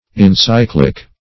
Encyclic \En*cyc"lic\, Encyclical \En*cyc"li*cal\, n.